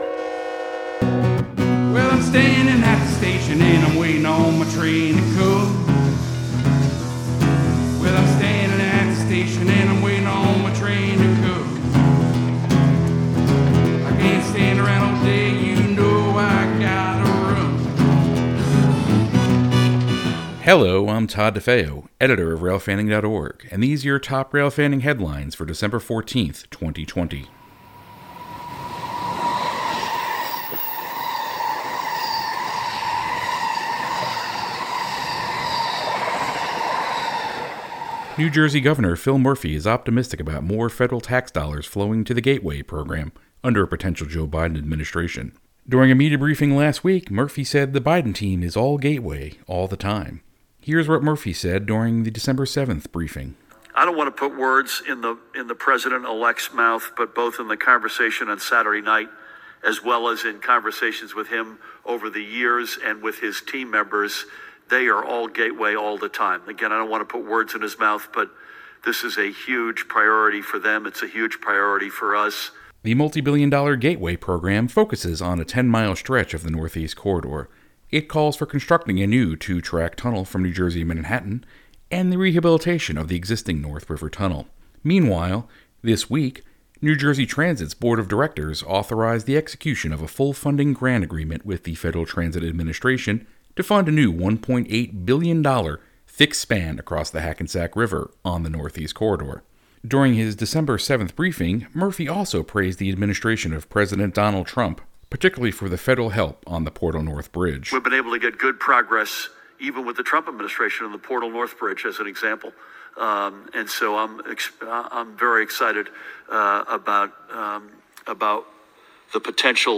Sound Effects
• Diesel Horn: Recorded at the Southeastern Railway Museum on Nov. 14, 2020.
• Steam Train: 1880s Train, recorded Sept. 12, 2020, in Hill City, South Dakota